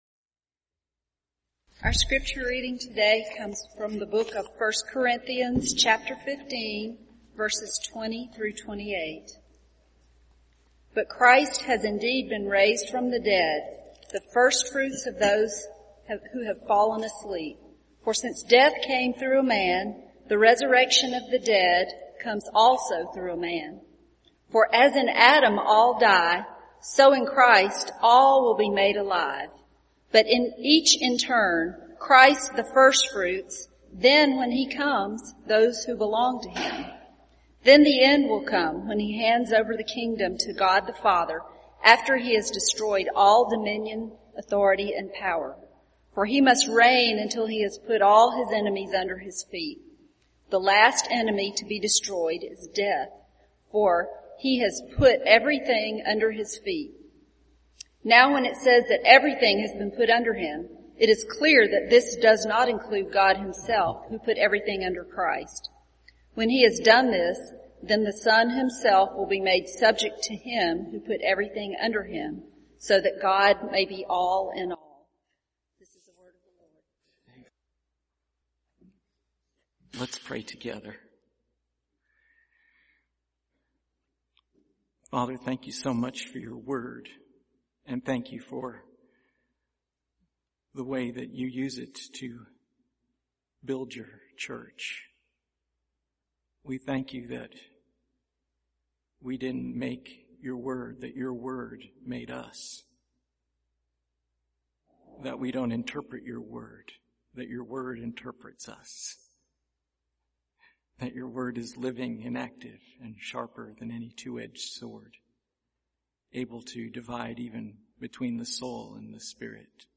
Passage: 1 Corinthians 15:20-2:28 Service Type: Sunday Morning